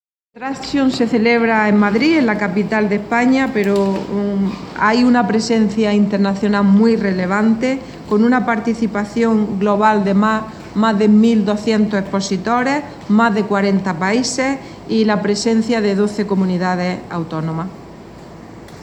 Declaraciones Carmen Ortiz sobre Fruit Attraction 2016